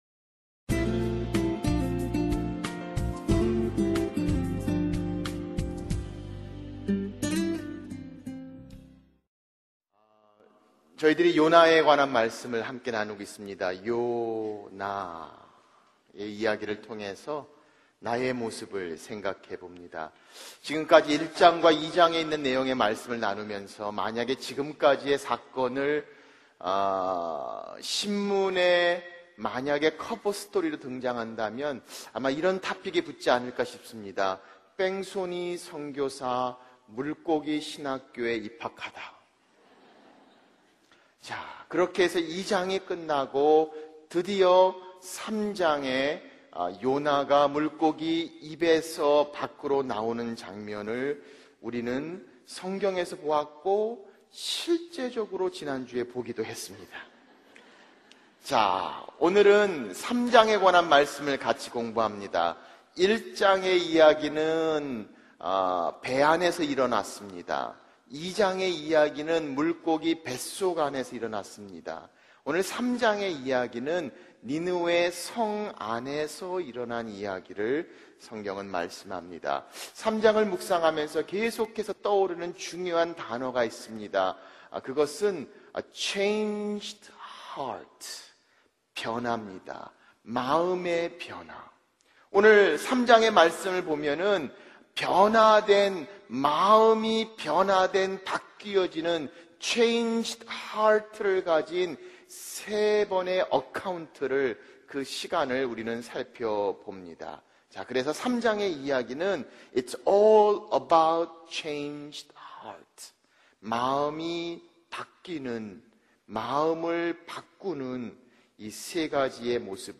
설교 | (3) 니느웨 다이어트